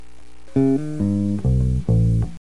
(bass1)
si_se_acabo_bass1.mp3